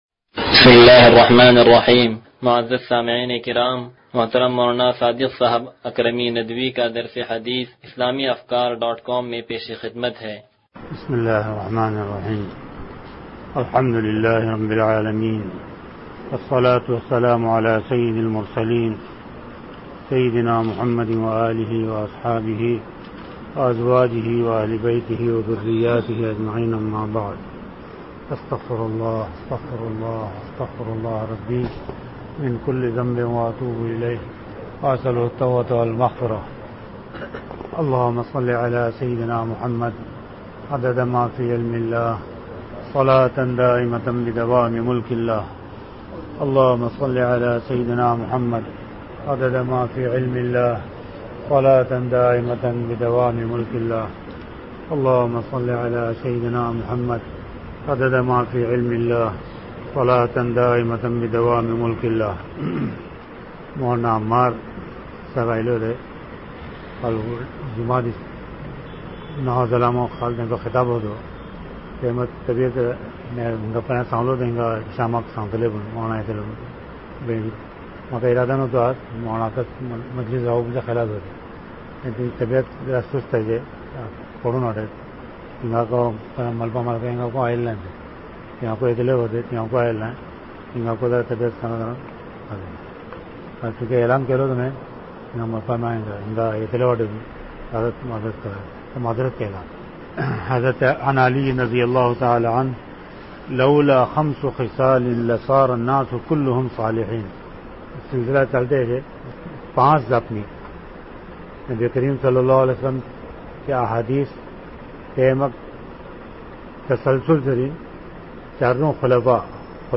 درس حدیث نمبر 0110